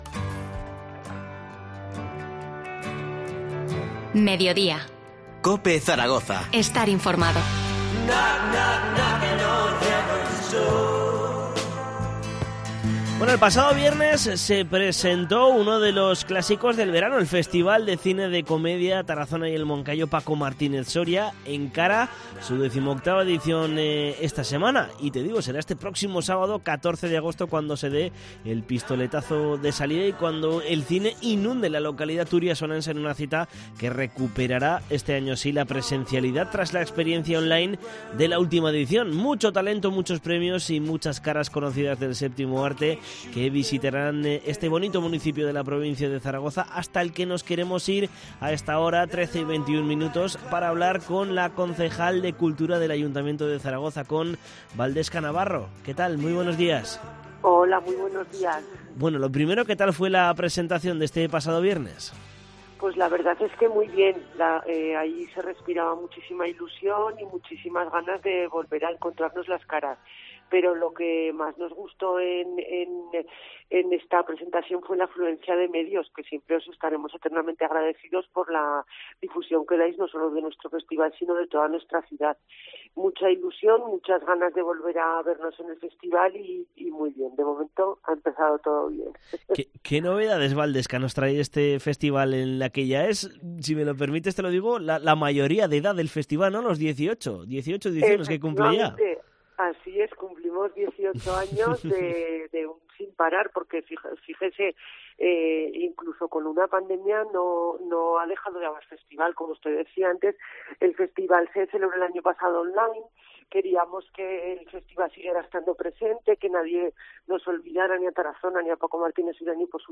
Entrevista a Waldesca Navarro, concejal de cultura del Ayuntamiento de Tarazona. 09-08-21
Este lunes también ha pasado por los micrófonos de COPE Zaragoza la concejal de cultura del Ayuntamiento de Tarazona, Waldesca Navarro, que también ha hablado sobre el 18 cumpleaños de esta cita cinematográfica: "Se respira muchísima ilusión y muchísimas ganas de volver a vernos las caras en el festival".